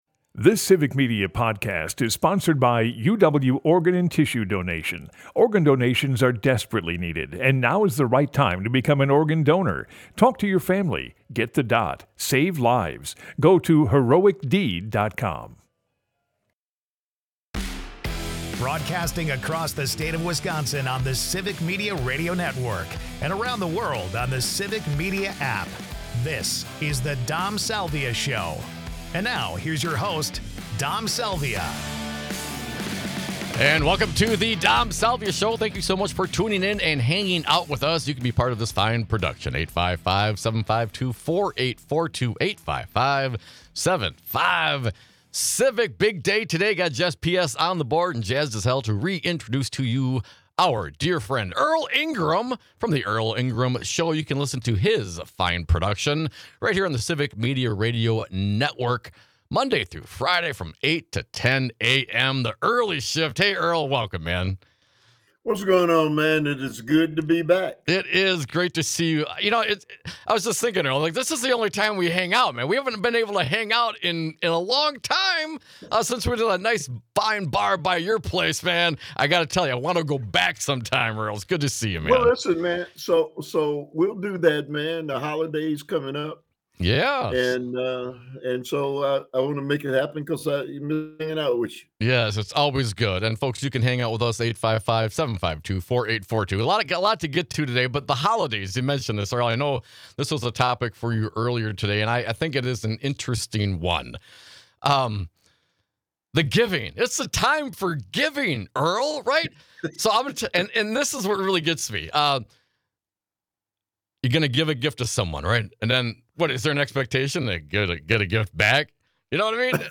Presents and Presence (Hour 1) Guests